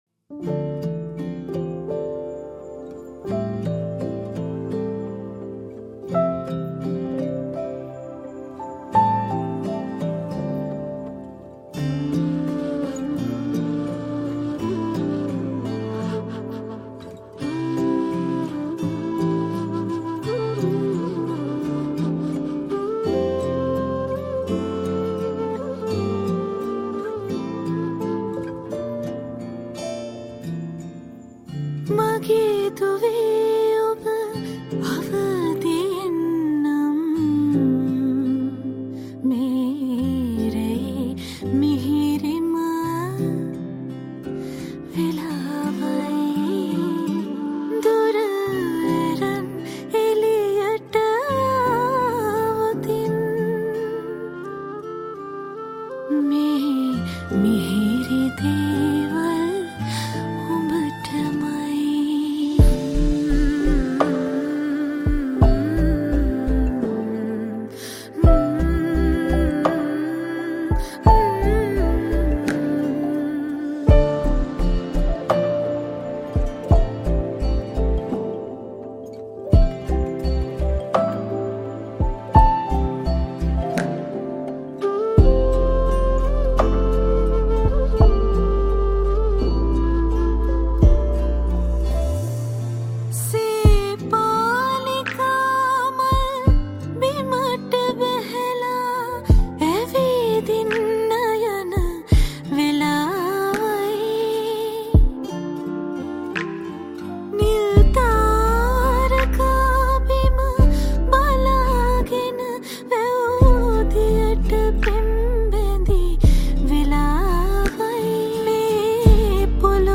Guitars
Flute